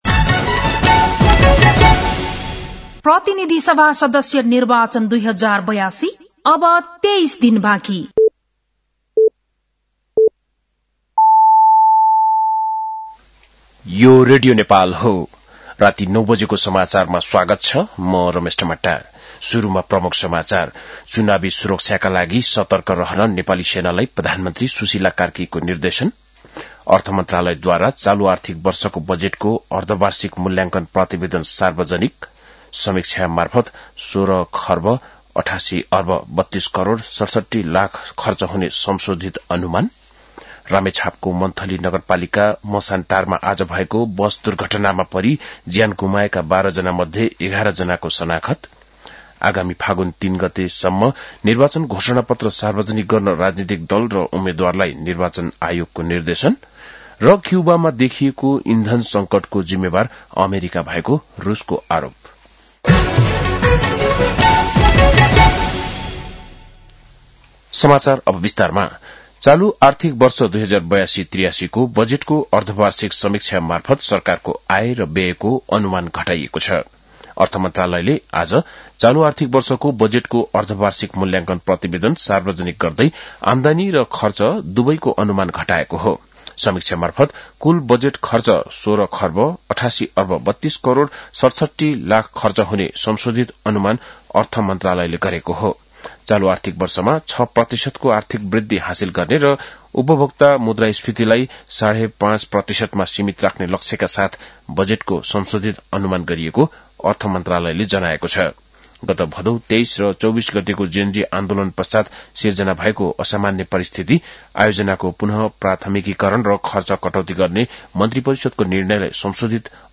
बेलुकी ९ बजेको नेपाली समाचार : २७ माघ , २०८२
9-pm-nepali-news-10-27.mp3